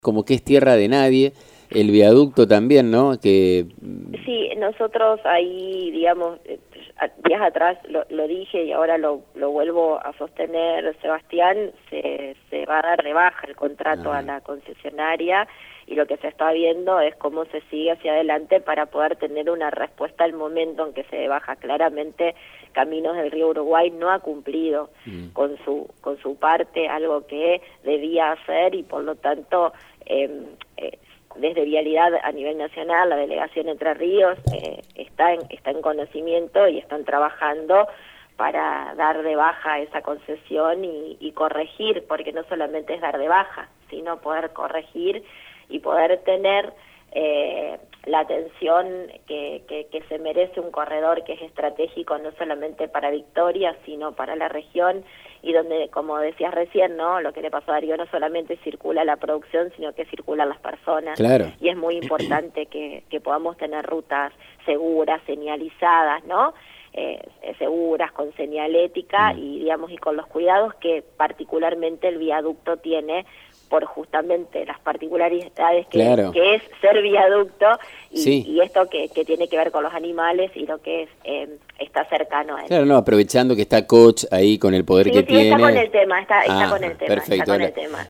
Laura Stratta Vicegobernador de la Provincia de Entre Ríos estuvo en contacto con “Burro de Arranque” y hablo sobre la concesión del Viaducto Rosario – Victoria.